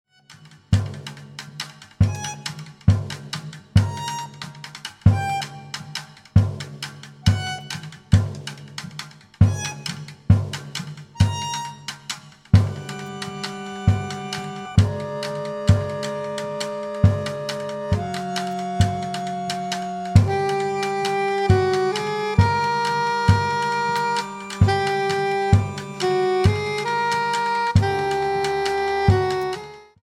soprano sax, bassclarinet
accordion
double bass
drums